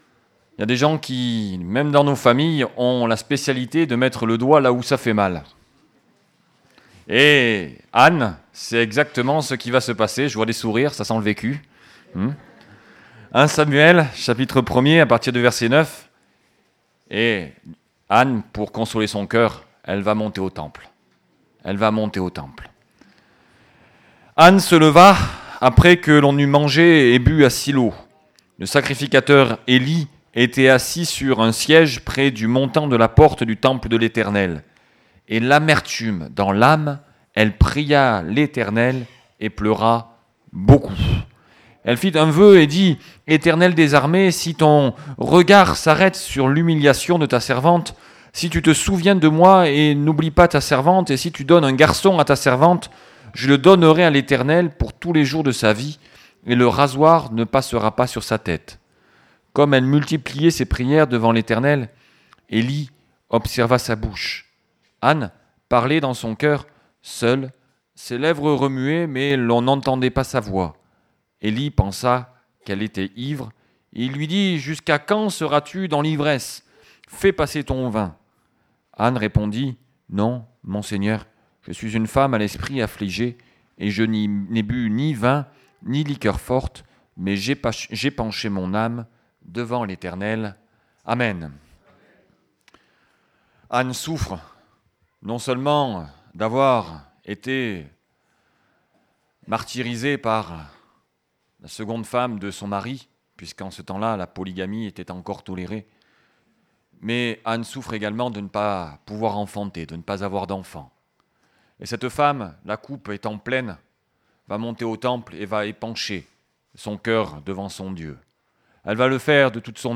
Message audio